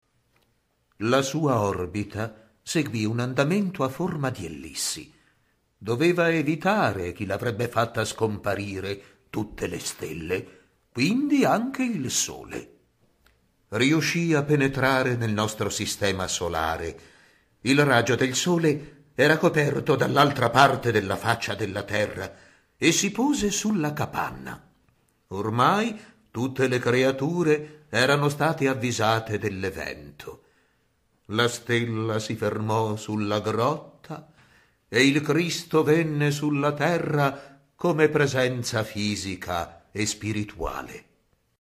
AUDIO narratore